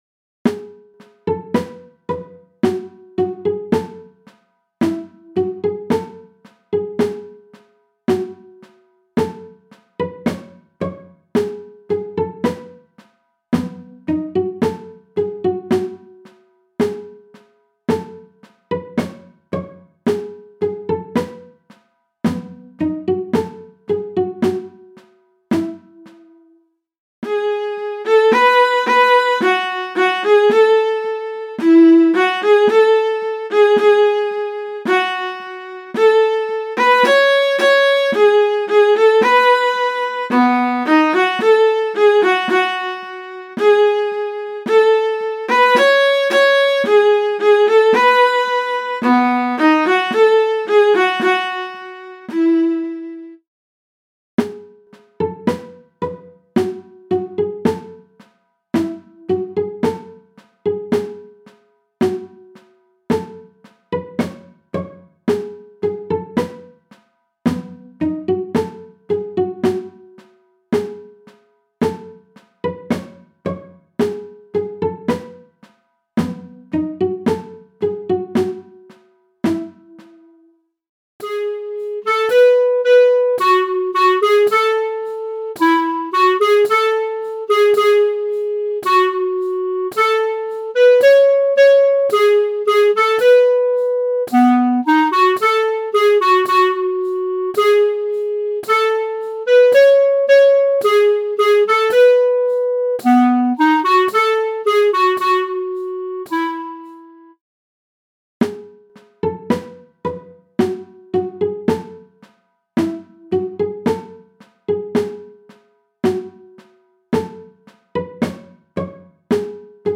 >>> Hörprobe :
MIDI von 2012 [5.418 KB] - mp3